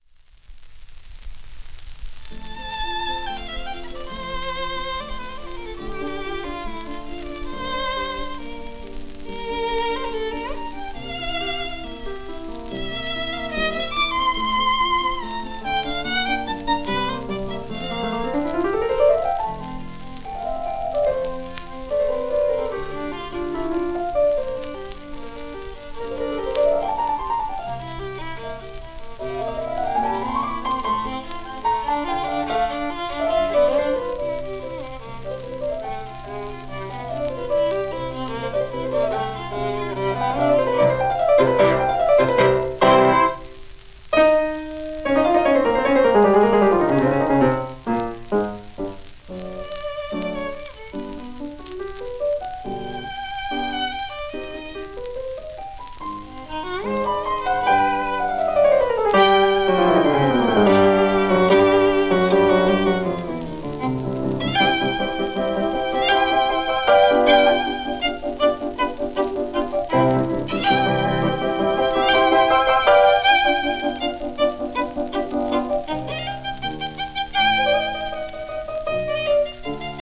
戦後の米国録音